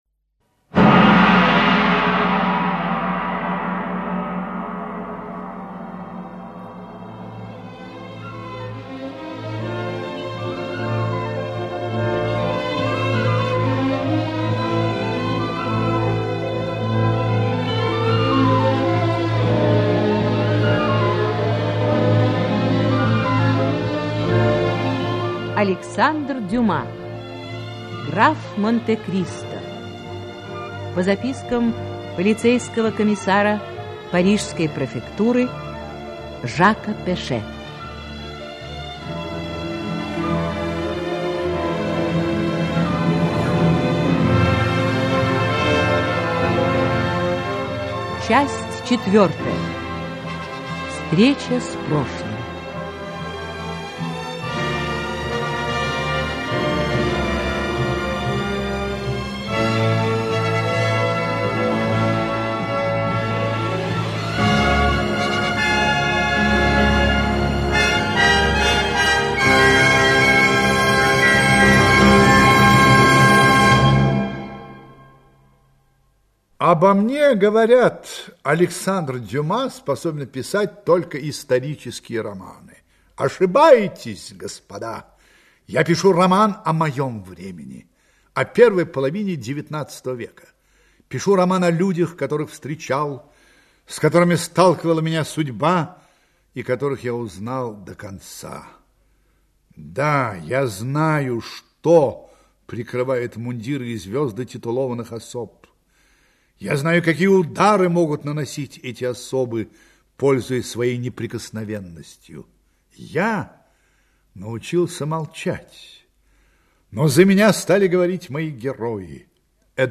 Аудиокнига Граф Монте-Кристо (спектакль) Часть 4-я. Встреча с прошлым | Библиотека аудиокниг